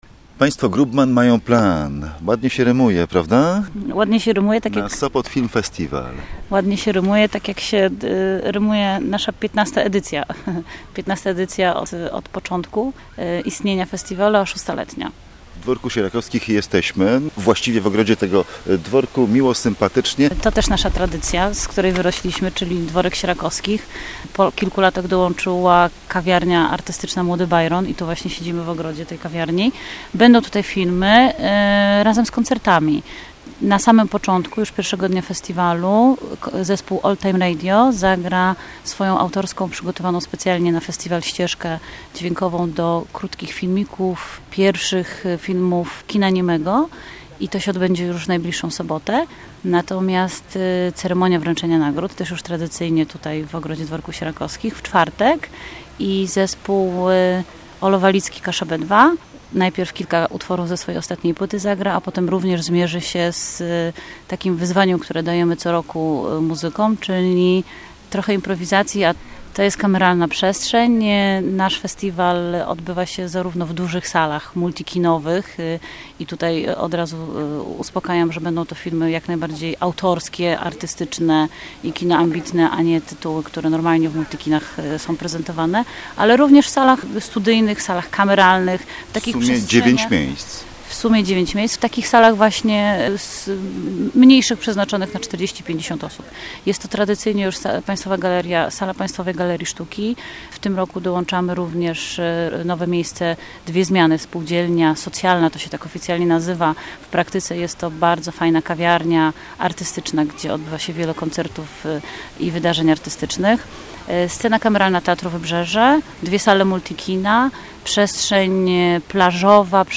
Posłuchaj rozmowy o Sopot Film Festiwal